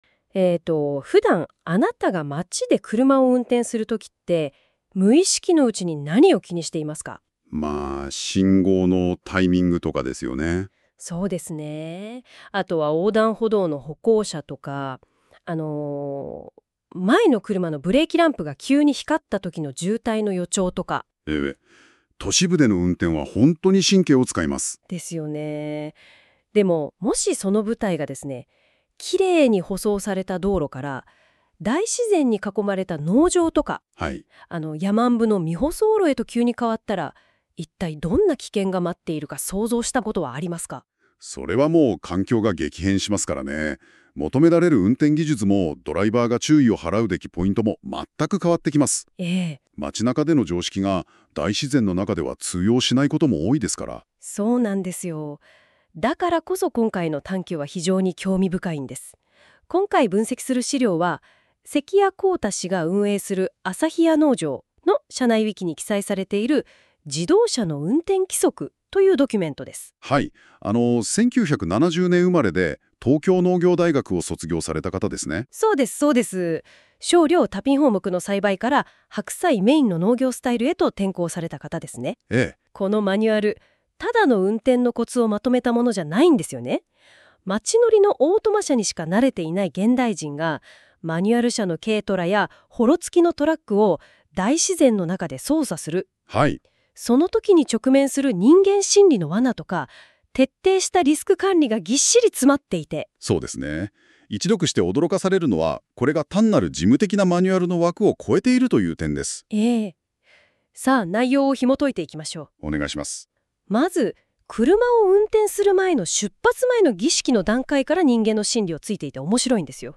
このページ内容は音声解説で別角度からも楽しめますのでご利用ください。